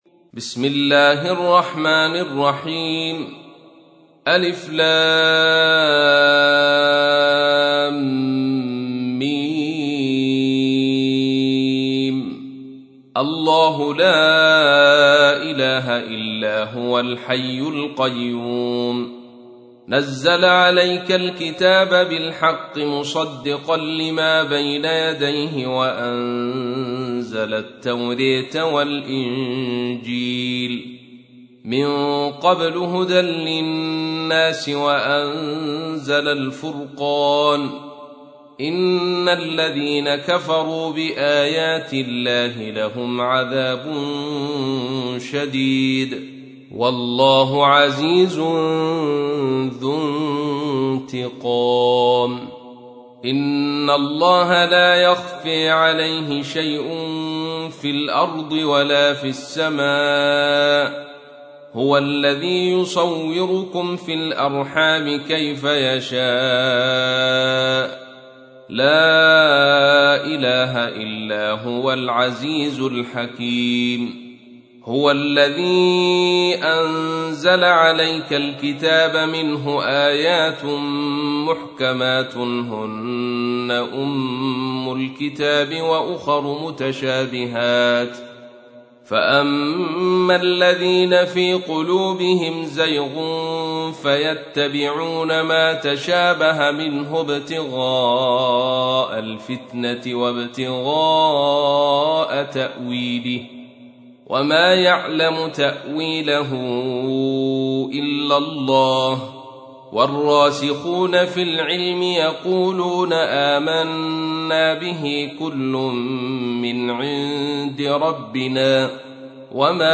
تحميل : 3. سورة آل عمران / القارئ عبد الرشيد صوفي / القرآن الكريم / موقع يا حسين